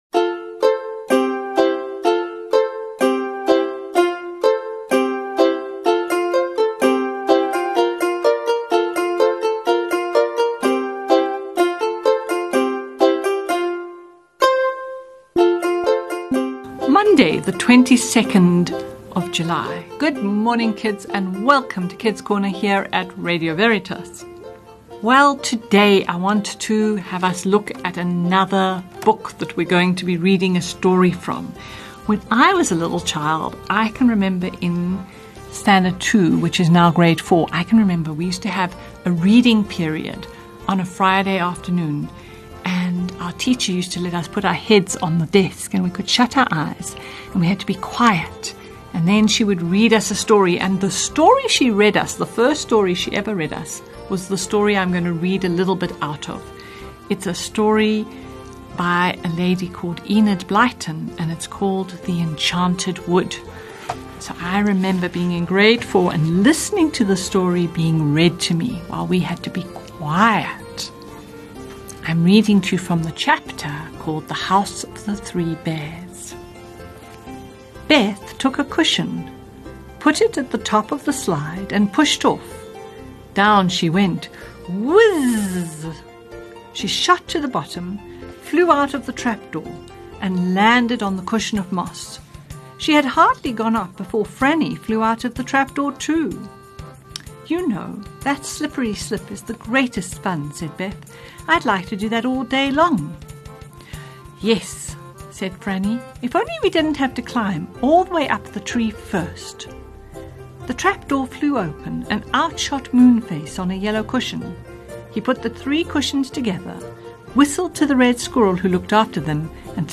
Reading: An extract from the book: The Enchanted Wood by Enid Blyton.